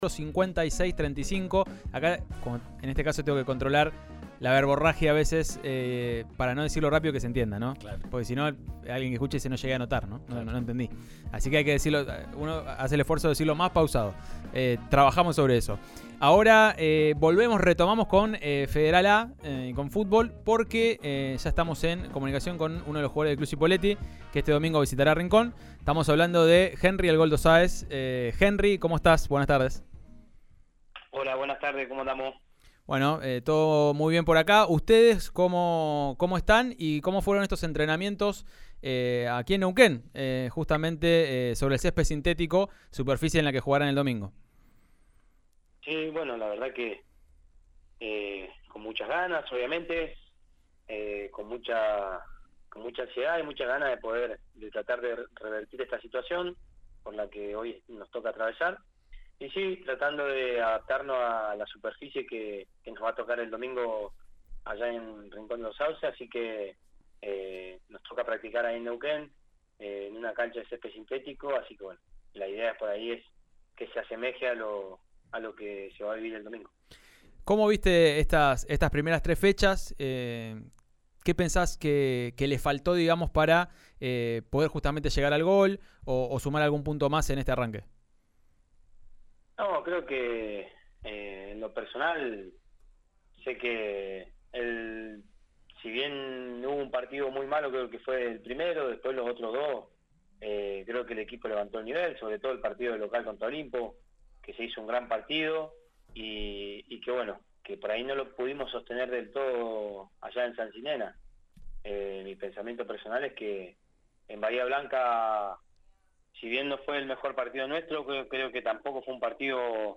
Nos vamos a enfrentar a un rival durísimo en una cancha con una superficie complicada «, aseguró el delantero en dialogo con «Subite al Podio» (de lunes a viernes de 17:30 a 19 por RN Radio ).